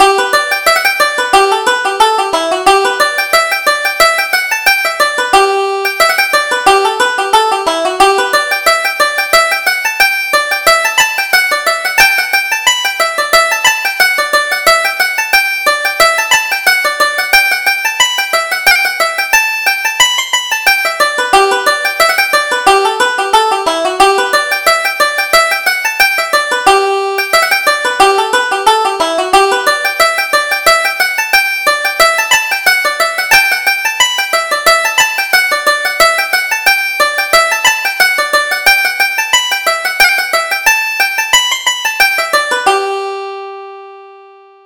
Reel: The Merry Merchant